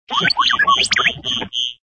SRobotAlarm.ogg